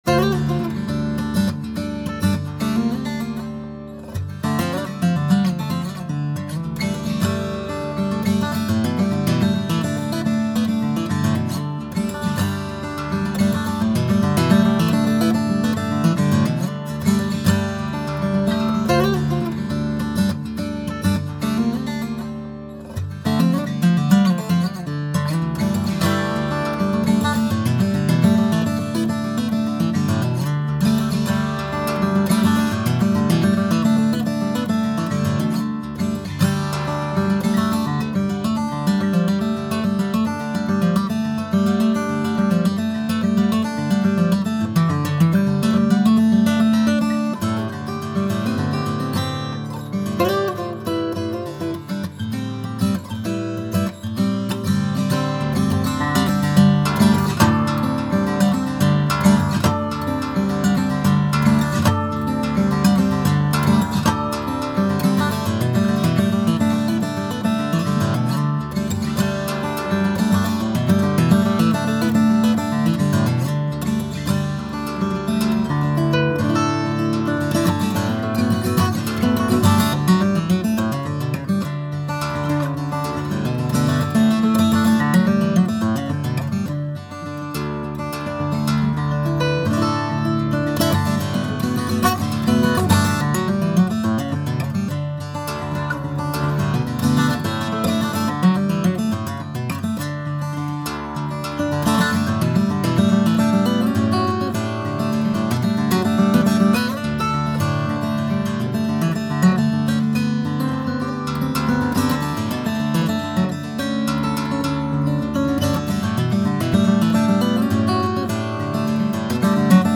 with a single mic mounted near the bridge.